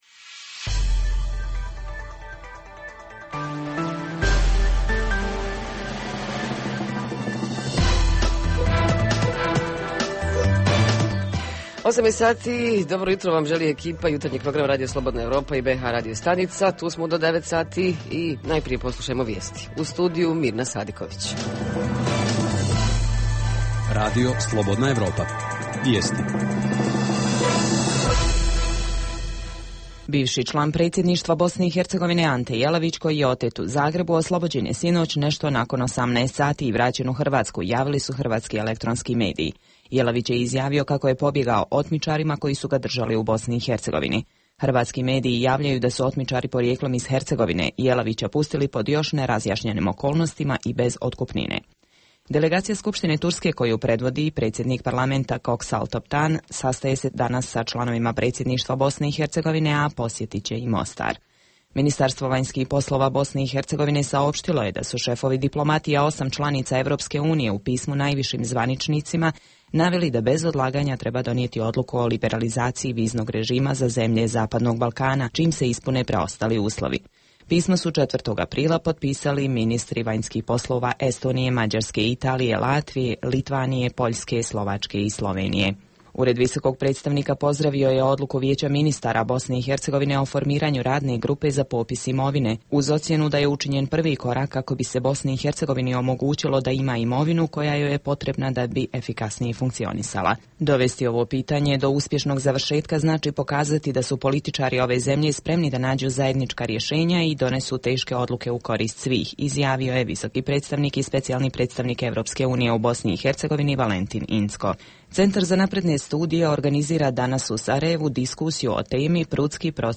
Jutarnji program za BiH koji se emituje uživo - govorimo o vatrogasnim jedinicama: kako su opremljene, ima li dovoljno kadra, da li su dobro obučeni, imaju li svu neophodni zaštitnu ličnu opremu, itd.. Redovna rubrika Radija 27 petkom je “Za zdrav život". Redovni sadržaji jutarnjeg programa za BiH su i vijesti i muzika.